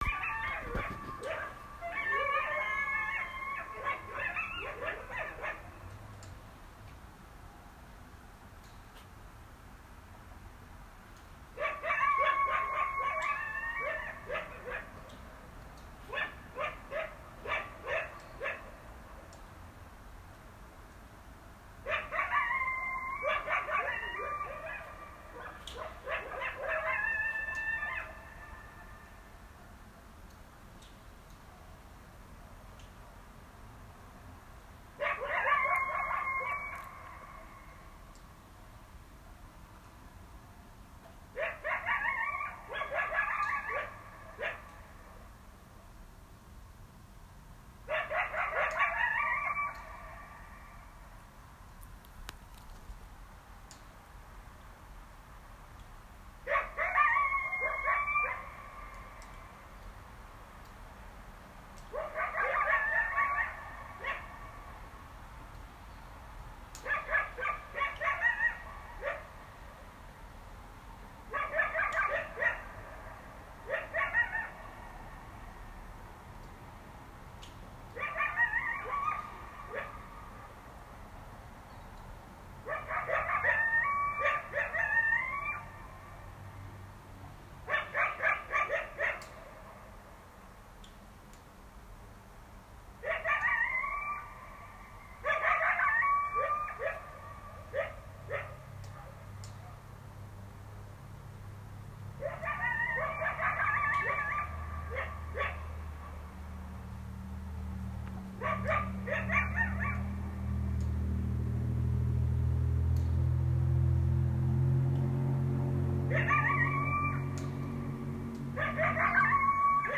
Coyote Voicings: Howls, Yips, Barks, & More | Coyote Yipps
Three 20-month-old siblings give this amazing concert after sirens sound at 9 pm